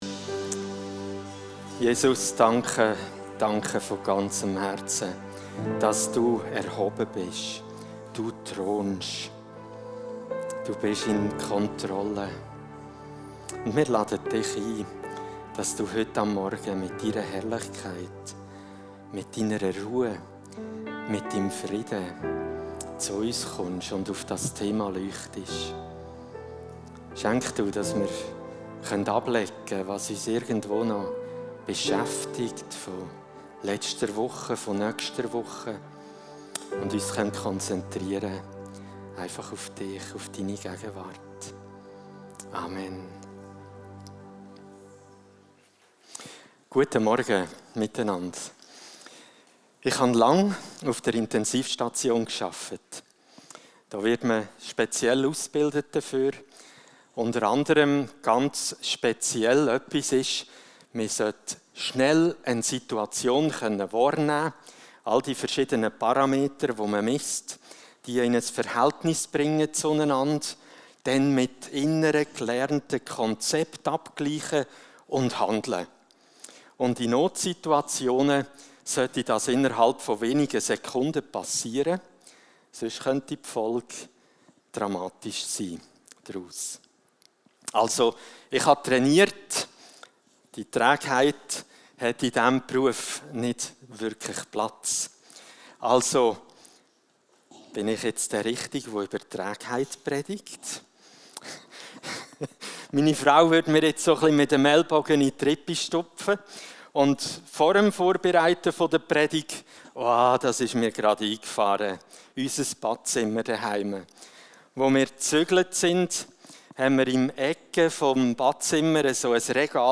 Trägheit ~ Zollhaus | Predigt Podcast